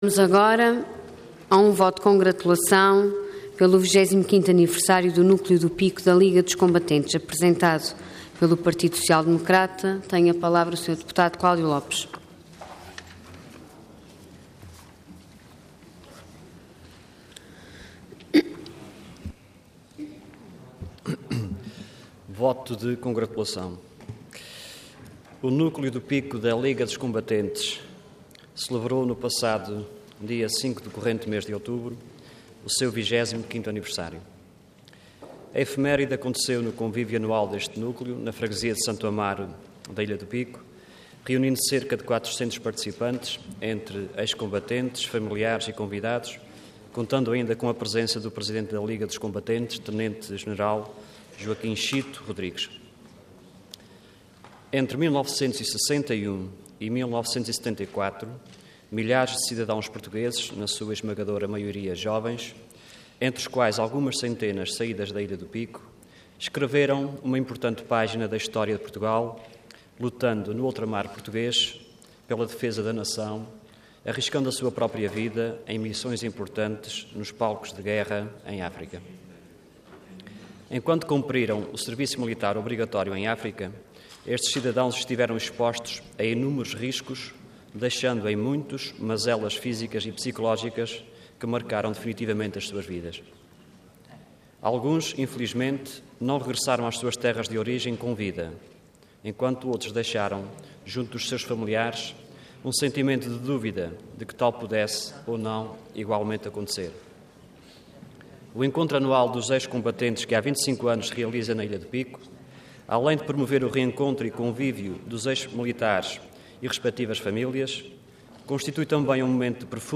Detalhe de vídeo 17 de outubro de 2013 Download áudio Download vídeo Processo X Legislatura 25.º Aniversário do Núcleo do Pico da Liga dos Combatentes. Intervenção Voto de Congratulação Orador Cláudio Lopes Cargo Deputado Entidade PSD